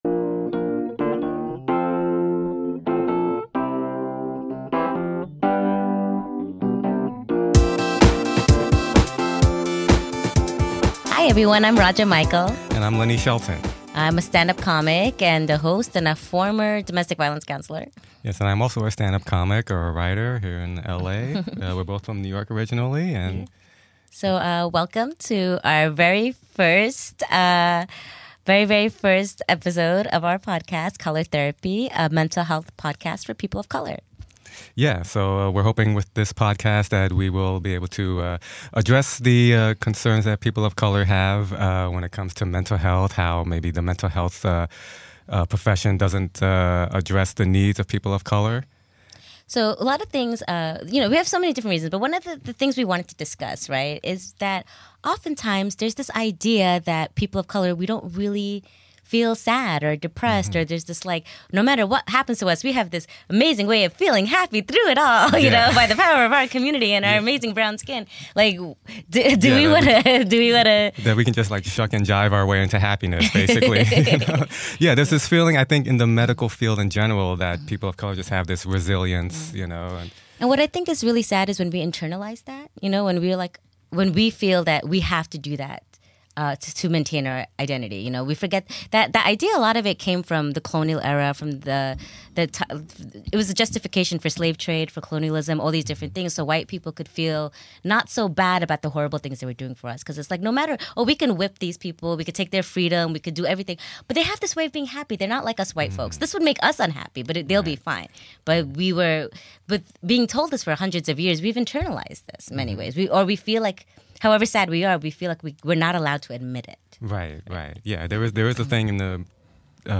Color Therapy discusses the particular struggles POC face when battling mental illness, including those triggered by the societal trauma that comes with being a POC. Through humorous conversation, we make these issues relevant by touching on a wide variety of topics. Featuring interviews with a diverse roster of guests including comedians, artists, advocates, mental health experts, and activists sharing their journeys in mental health, addressing the specific needs PoC have that the mainstream mental health community has failed to recognize.
Program participants were granted access to professional recording equipment & personal supplied by UCB Comedy Podcast Network as well as the opportunity present their shows via specific show feeds throughout the Podcast Network.